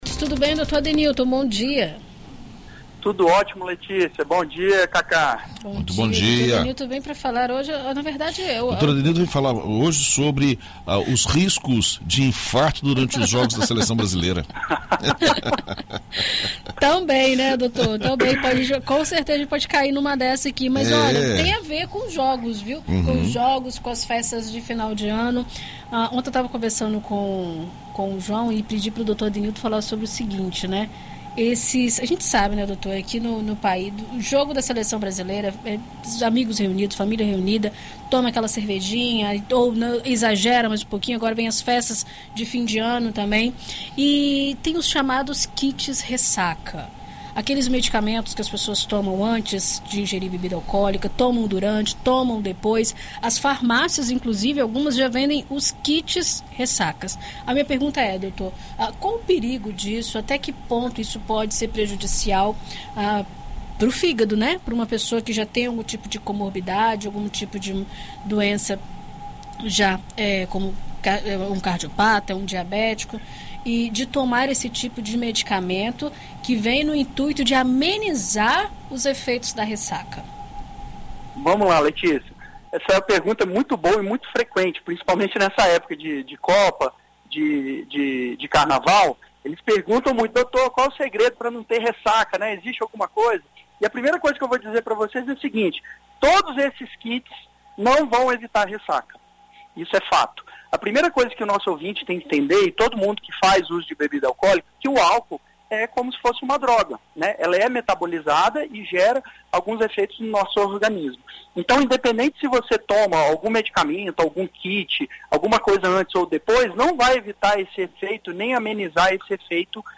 Na BandNews FM, médico intensivista fala dos perigos que os remédios que prometem curar ressaca podem trazer ao fígado